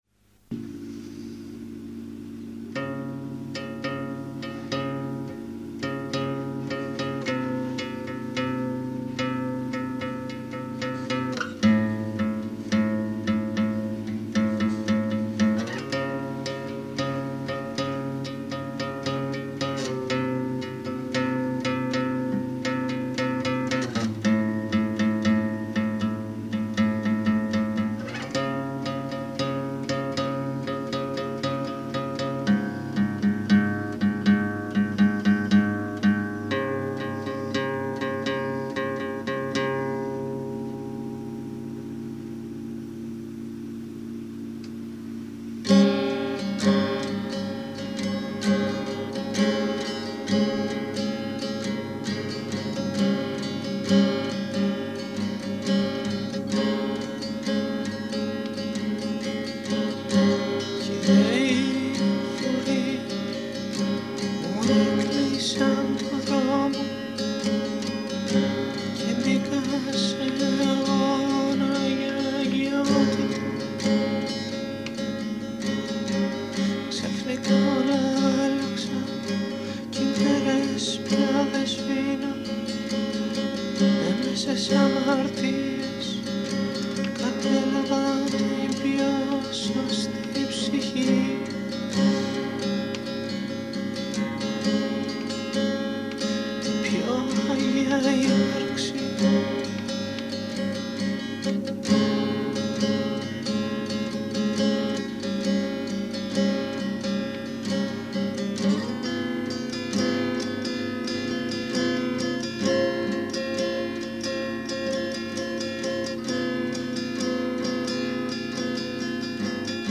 Σημείωση: Η κακή ποιότητα του ήχου δεν οφείλεται στα mp3.